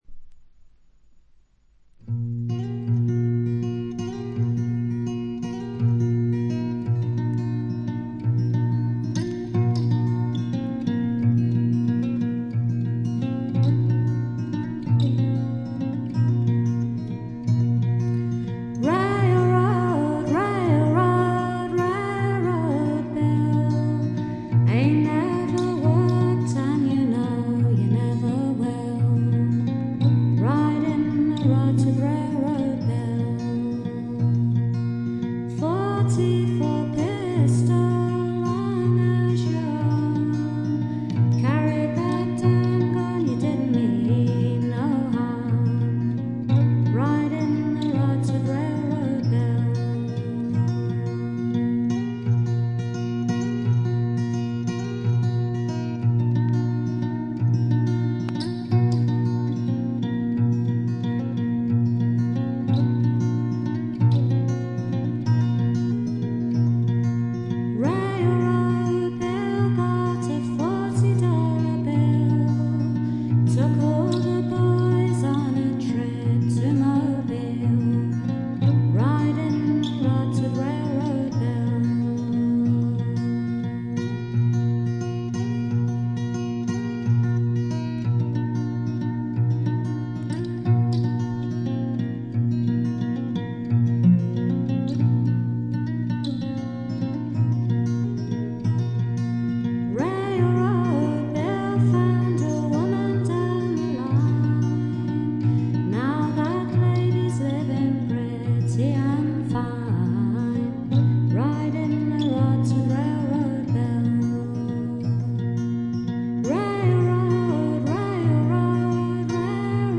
演奏面は彼女自身が奏でるギター、ブズーキだけと非常にシンプルなもの。
アルバム全体はしっとりした雰囲気で、不思議な浮遊感があり少しくぐもったかわいい歌声が「夢の世界」を彷徨させてくれます。
試聴曲は現品からの取り込み音源です。